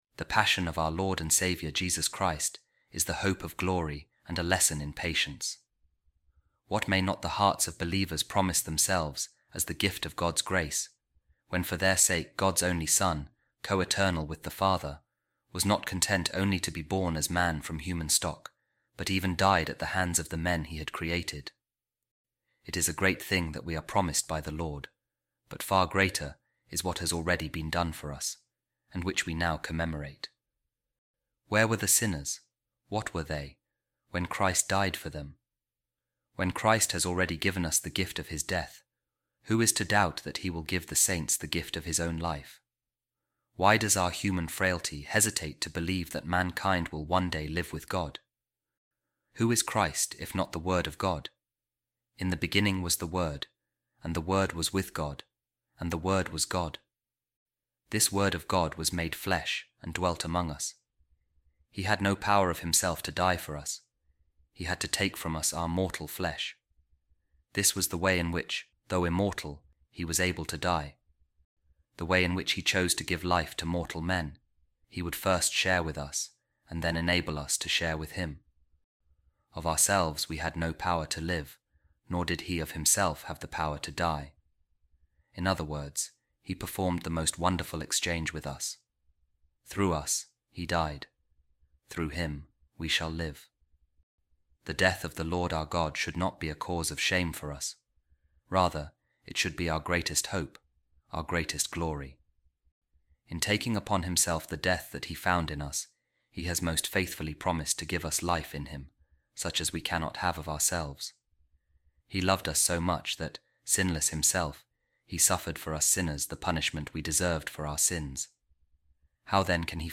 Office Of Readings | Monday Of Holy Week | A Reading From The Sermons Of Saint Augustine